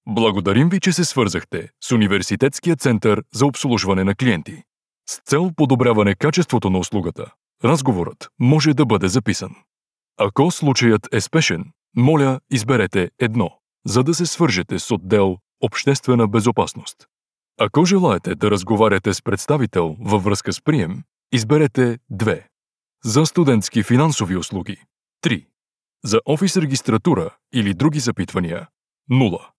Listen to male Bulgarian Voice Artist